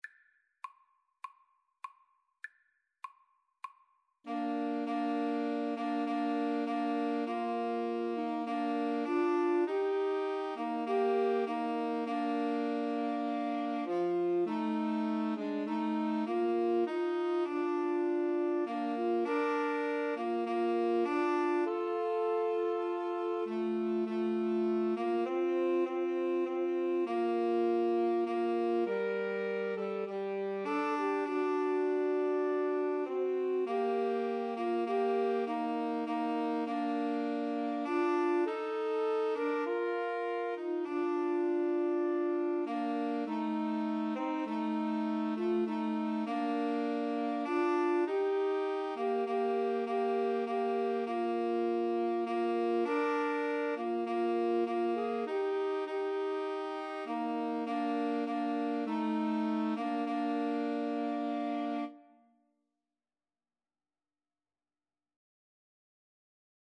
Soprano SaxophoneAlto SaxophoneTenor Saxophone
Woodwind Trio  (View more Easy Woodwind Trio Music)
Classical (View more Classical Woodwind Trio Music)